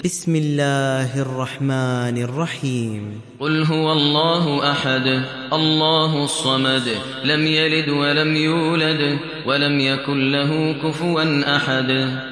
Hafs an Assim